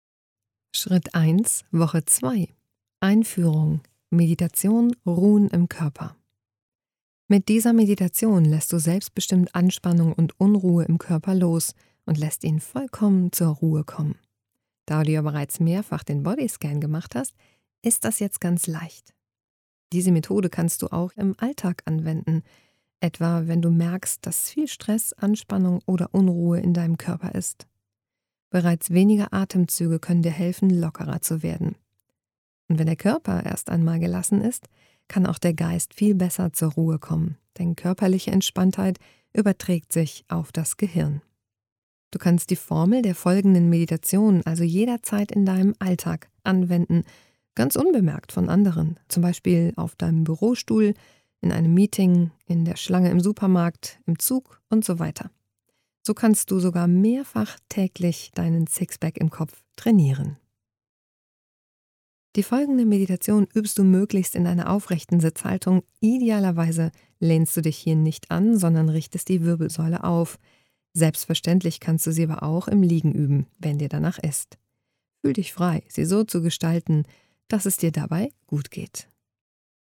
Meditations CD oder MP3 zum Buch. Starte mit 8-Wochen geführten Meditationen!